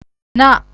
{na.}/{naa.} न